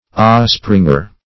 Osspringer \Os"spring*er\, n.